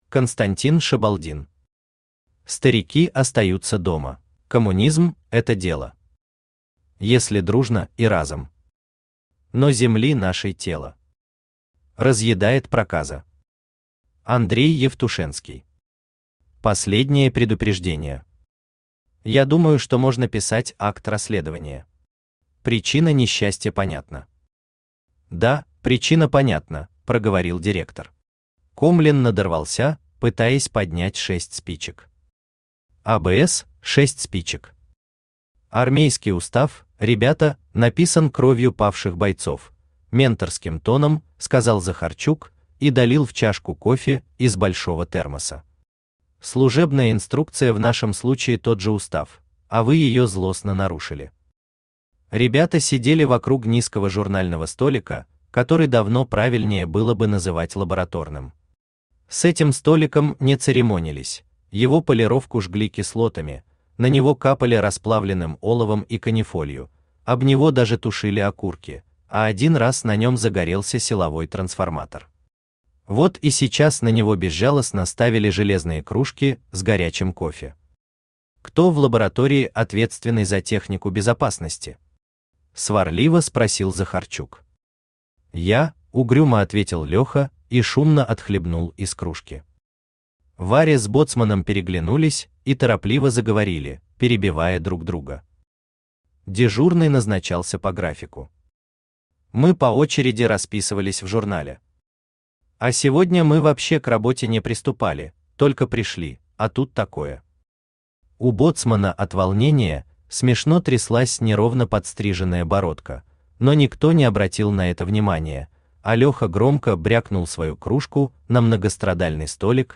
Aудиокнига Старики остаются дома Автор Константин Шабалдин Читает аудиокнигу Авточтец ЛитРес.